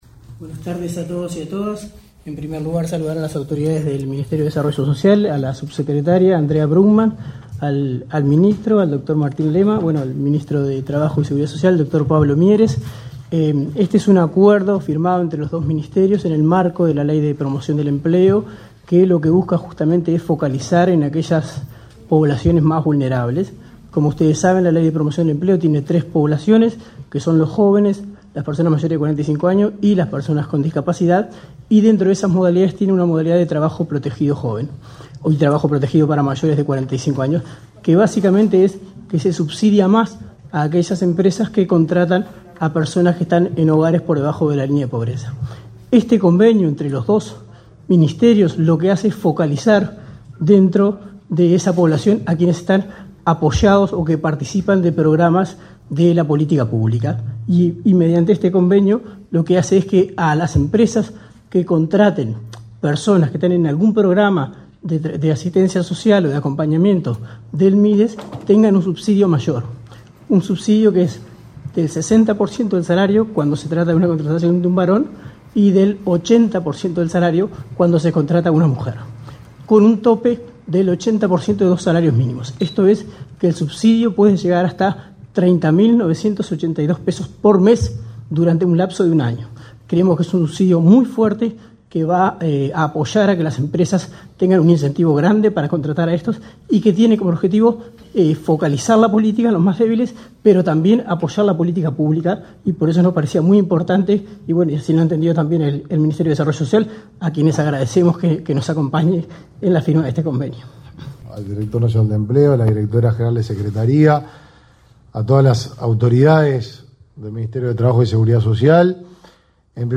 Conferencia de prensa por firma de convenio entre Ministerio de Trabajo y Mides
En el acto, realizado este 1 de abril, participaron los ministros Pablo Mieres y Martín Lema, y el director Nacional de Empleo, Daniel Pérez.